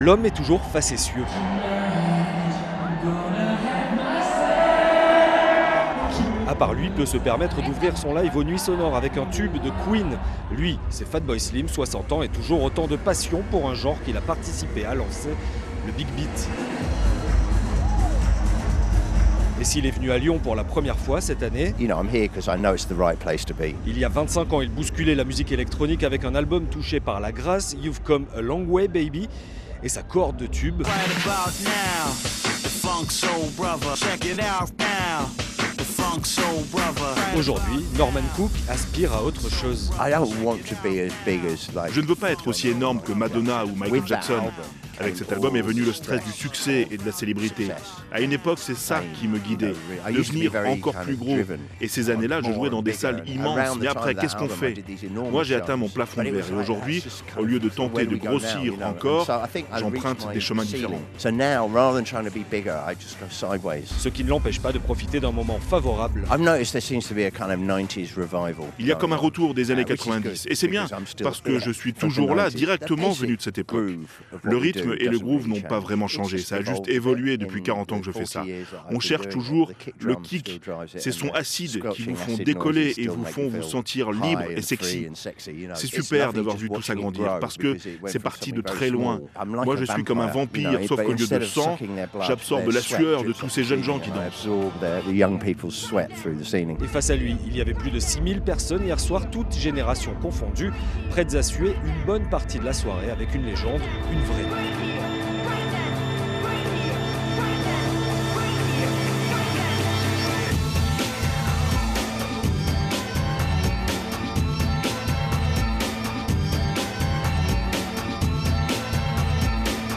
FRANCE INFO - Interview Fatboy Slim à Nuits sonores - 17/05/24
FRANCE-INFO_FATBOY_SLIM_NUITS_SONORES_.mp3